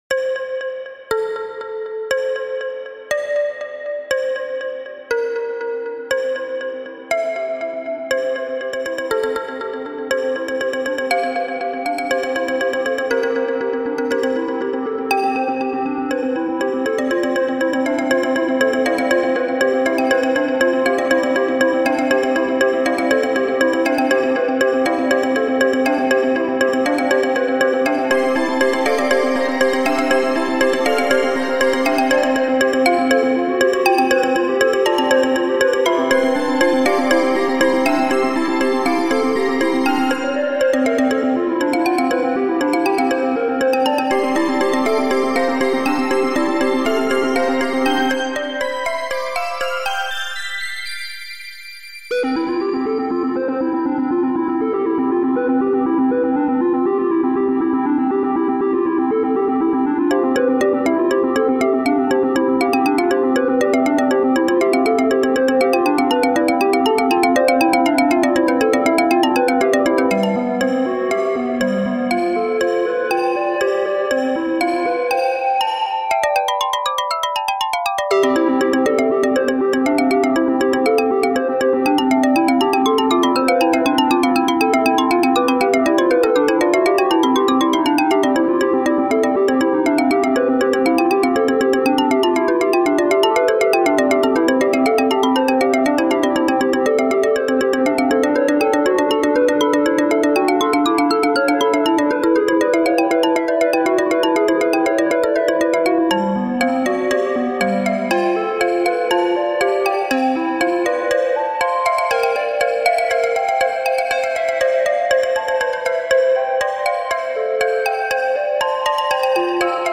Song time.
Hence the little wobbles here and there.
Pretty but uneventful, no rough edges to speak of.